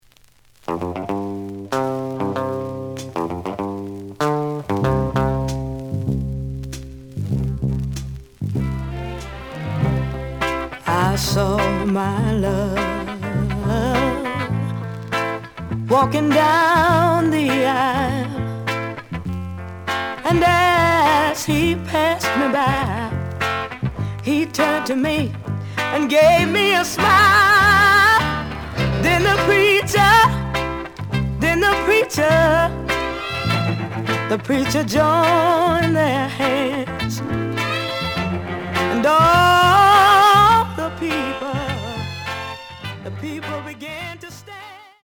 The audio sample is recorded from the actual item.
Looks good, but slight noise on both sides.)